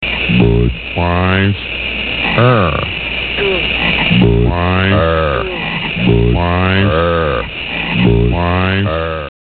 budweiser_werbung2.mp3